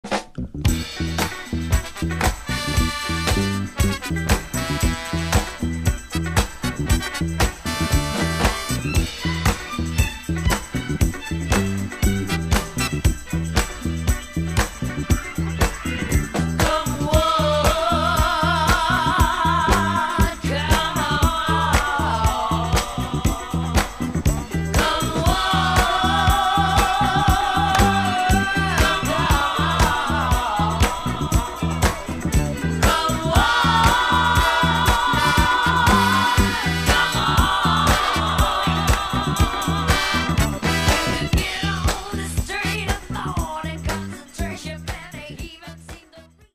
FREE SOUL/RARE GROOVE
ギター